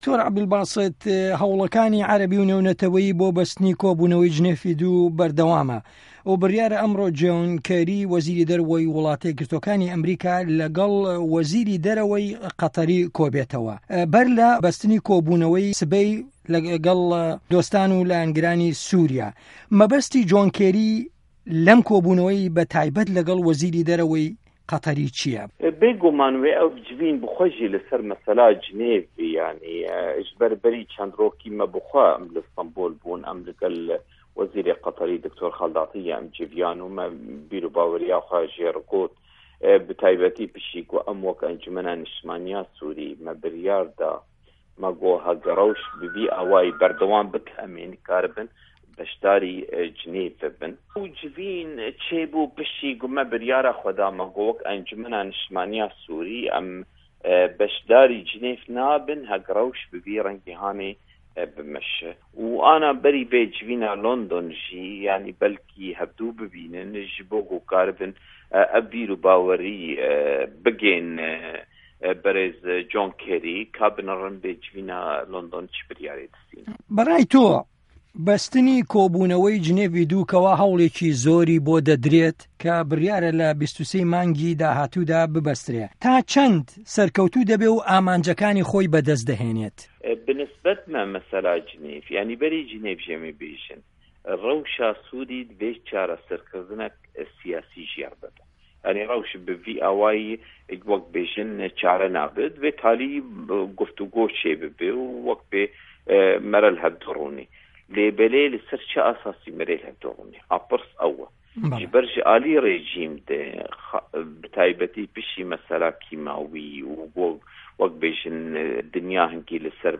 Hevpeyvîn bi Dr.Abdulbasit Seyda re